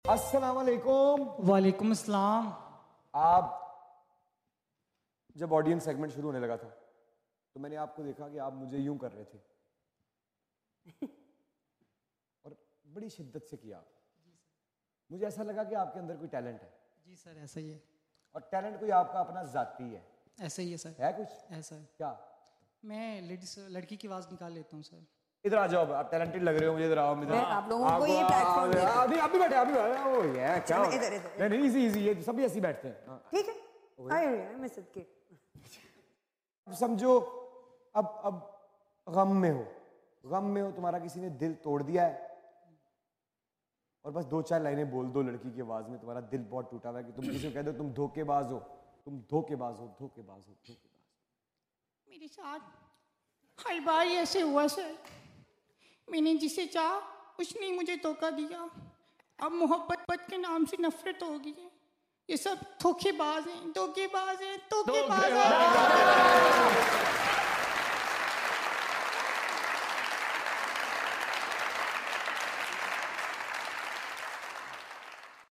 میں نے کوشش کی ہے لڑکی کی اواز نکالنے کی